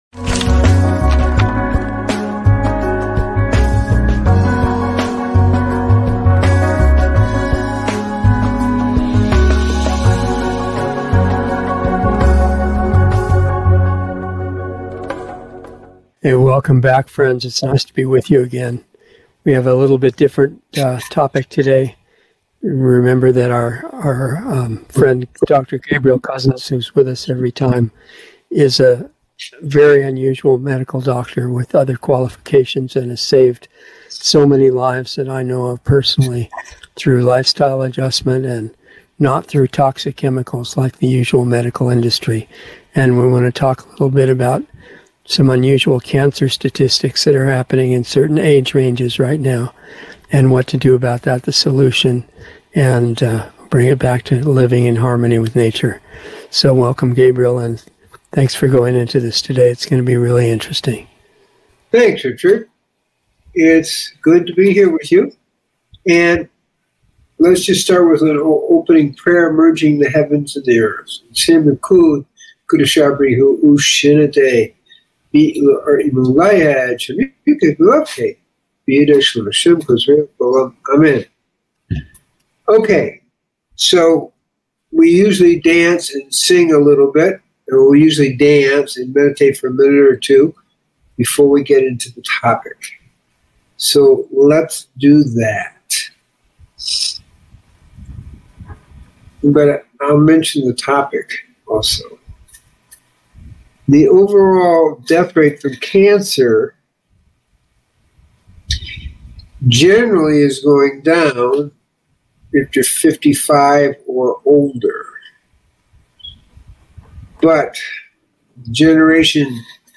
Decreasing Your Cancer Risk - Dialogs
A new LIVE series